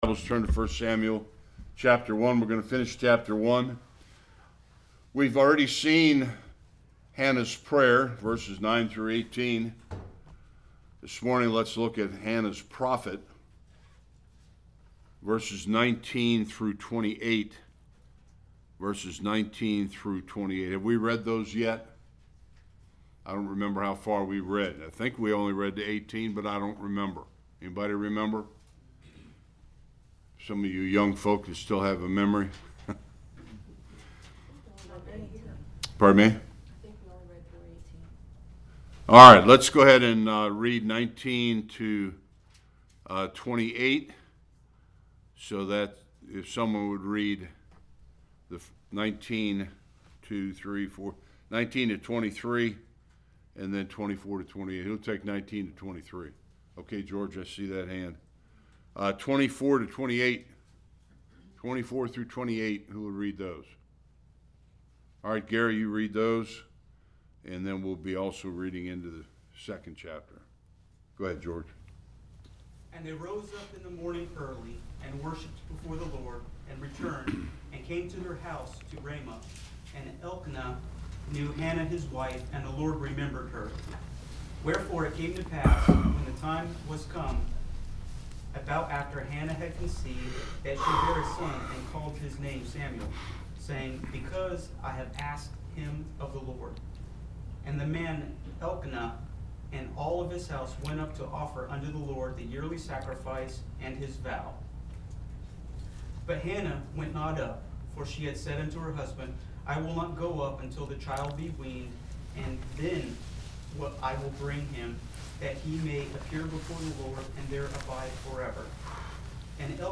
1-17 Service Type: Sunday School The birth of Samuel and his dedication to the Lord’s service at Shiloh.